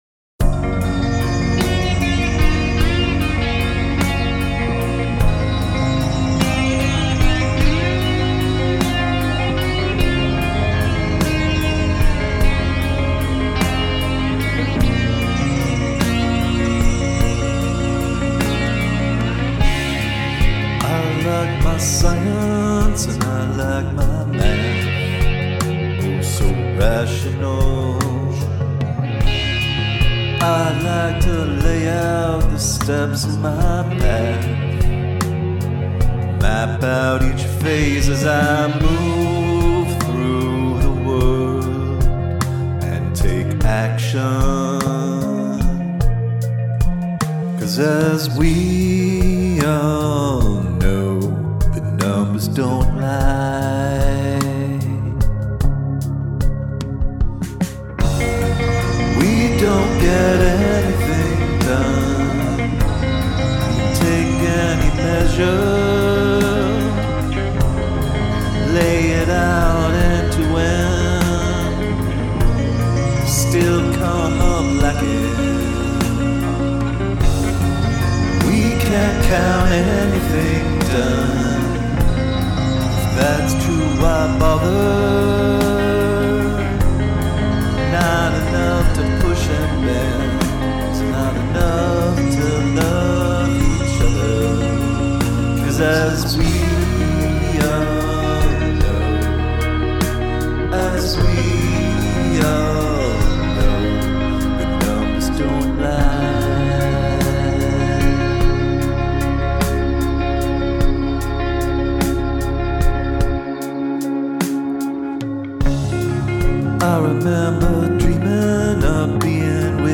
Use the Royal Road chord progression
(in key of c#, royal road in intro and pre-chorus)
I like the ideas a lot. some really nice sparkly synths.
Feels like I'm in a haze… shimmery.